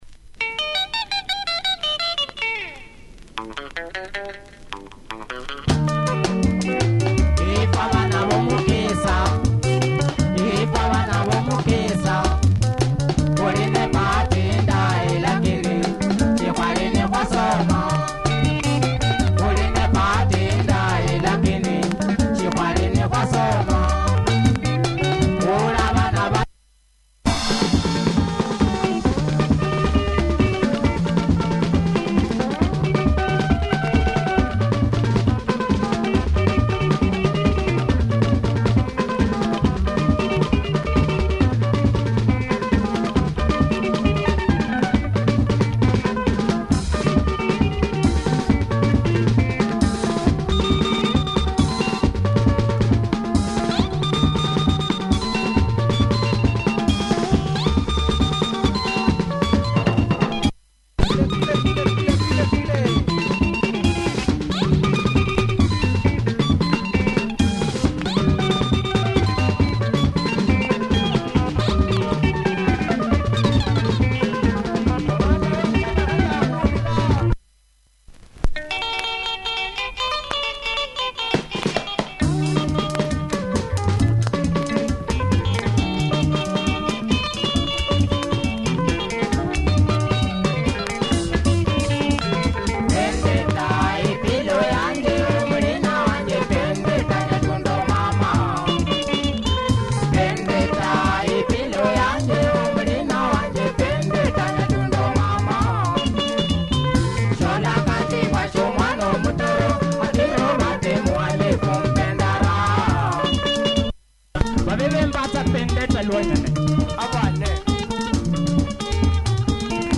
Tight Luhya Benga, heavy stick-drumming. Breakdown change.